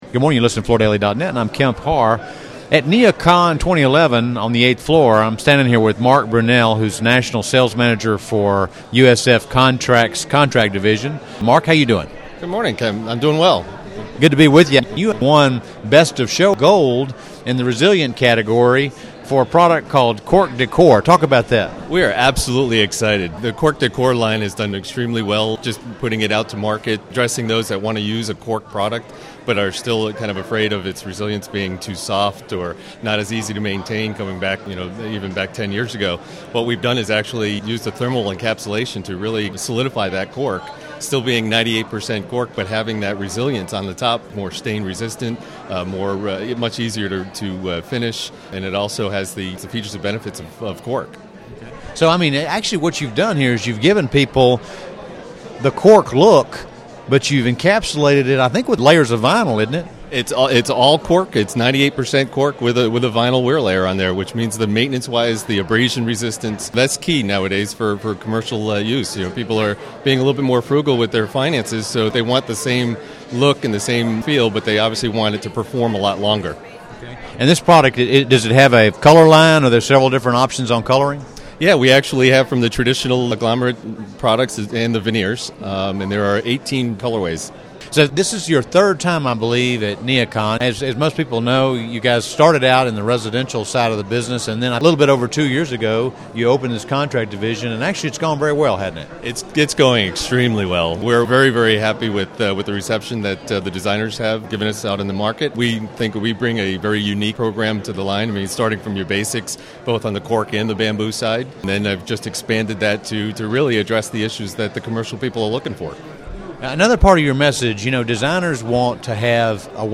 Listen to the interview to hear more details about this product and where these products are being used in the commercial market.